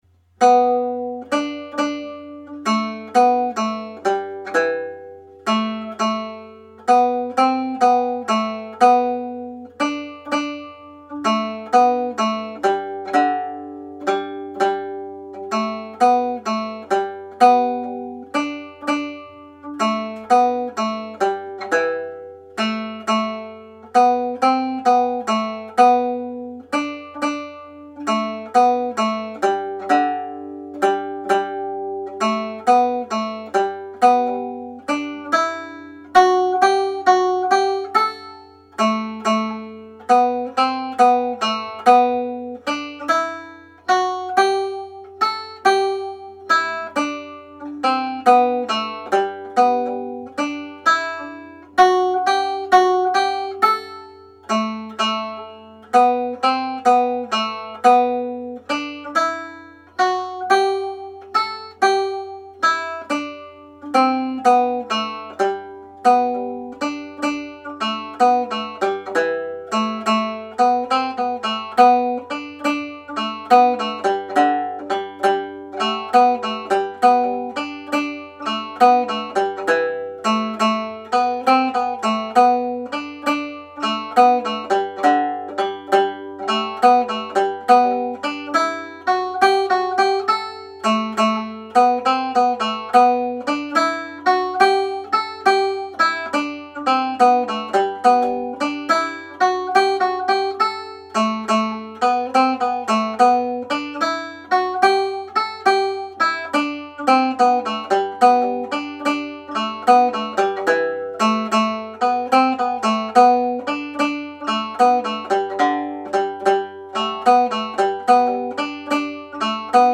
Slip jig (G Major)
played slowly
Dusty_Miller_slow_speed.mp3